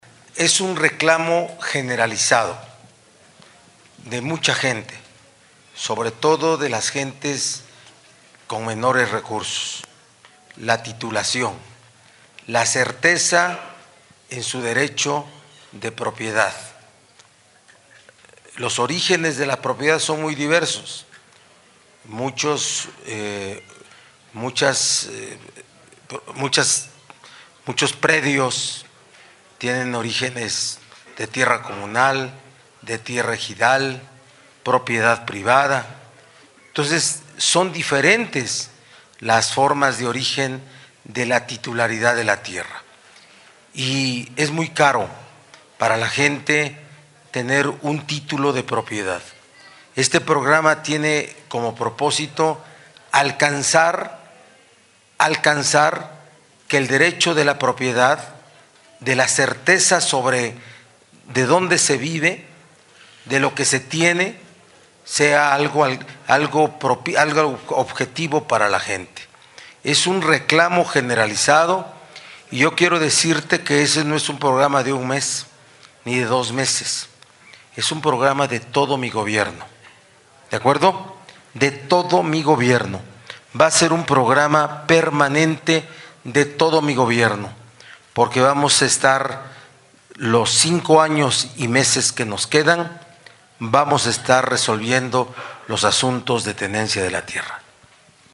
En rueda de prensa, el mandatario señaló que hay predios que tienen origen comunal, ejidal o de propiedad privada, lo que implica diferentes esquemas para obtener la titularidad de la tierra, con altos costos para algunos ciudadanos.